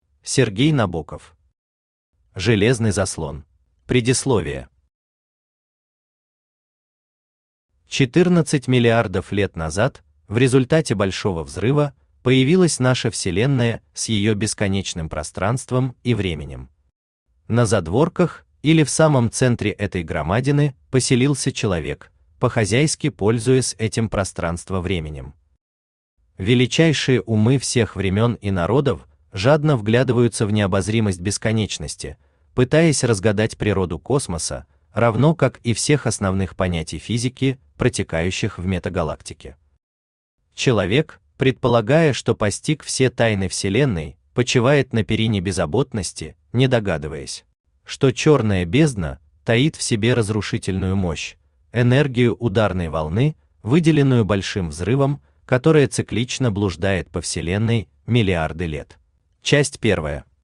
Аудиокнига Железный заслон | Библиотека аудиокниг
Aудиокнига Железный заслон Автор Сергей Набоков Читает аудиокнигу Авточтец ЛитРес.